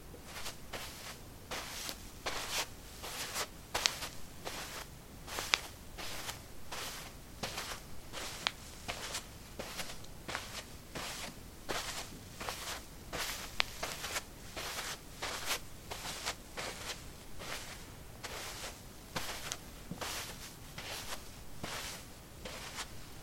脚步地毯 " 地毯 01a 赤脚走路
描述：在地毯上行走：赤脚。在房子的地下室用ZOOM H2记录，用Audacity标准化。
标签： 脚步 足迹 步骤
声道立体声